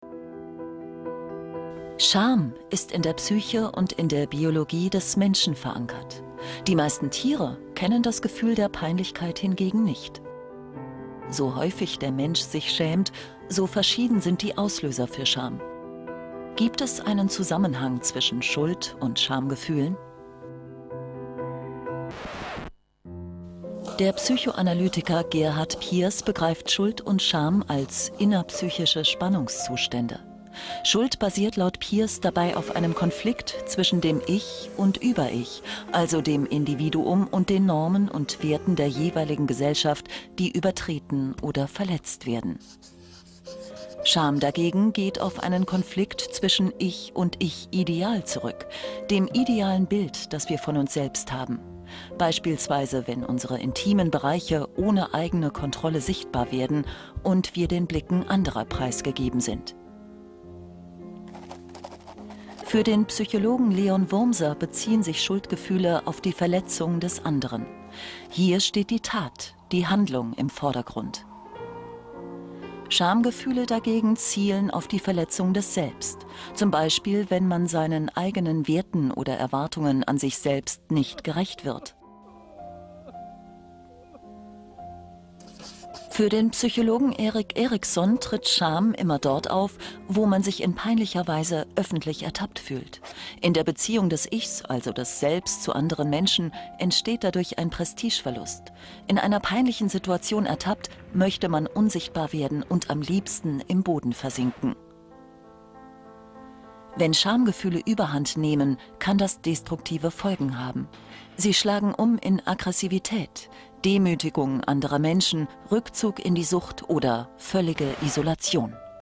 sehr variabel, markant
Mittel plus (35-65)
Commercial (Werbung)